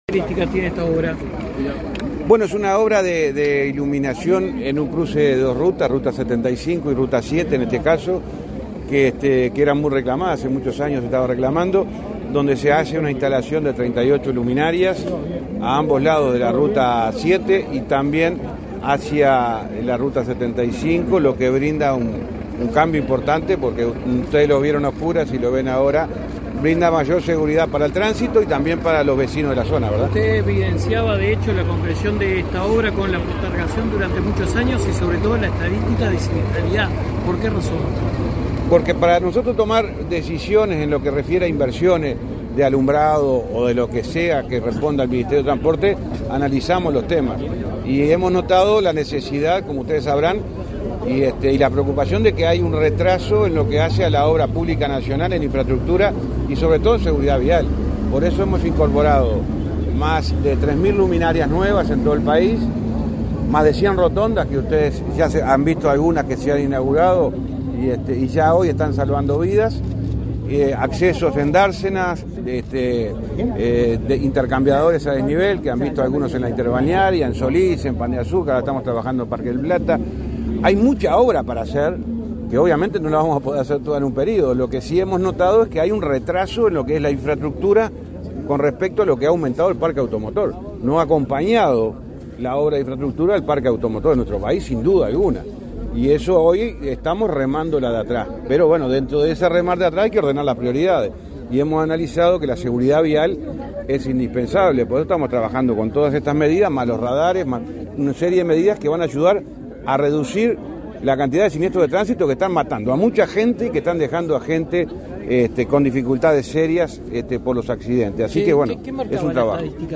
Declaraciones a la prensa del ministro de Transporte, José Luis Falero
Declaraciones a la prensa del ministro de Transporte, José Luis Falero 27/07/2023 Compartir Facebook X Copiar enlace WhatsApp LinkedIn Tras participar en la inauguración de 38 luminarias en las rutas n.° 7 y n.° 75, este 27 de julio, el ministro de Transporte y Obras Públicas, José Luis Falero, realizó declaraciones a la prensa.